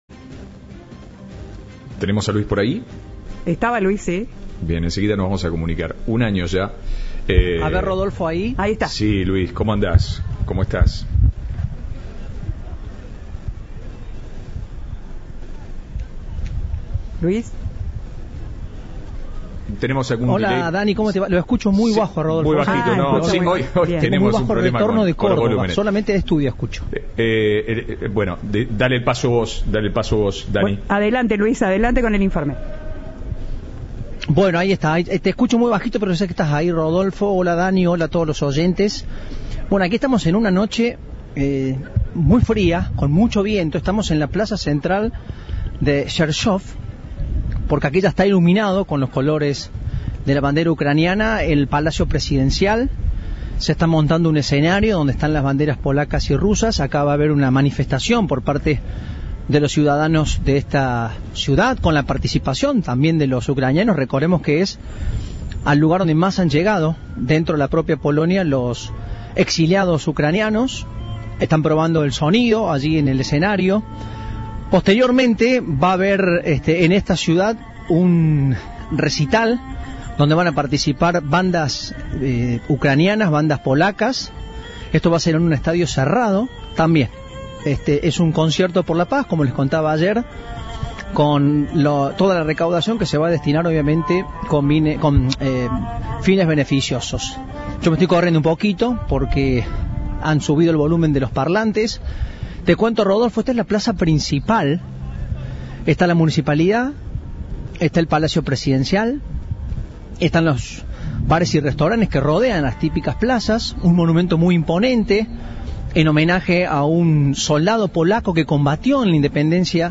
Rodeados de banderas blancas y rojas, y amarillas y azules, las primeras bandas comienzan a tocar en lo que será un concierto benéfico, que se llevará a cabo tras la movilización de los ciudadanos, que claman por el fin de la guerra.
Informe